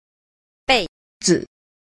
5. 輩子 – bèizi – bối tử (đời sống, cuộc đời)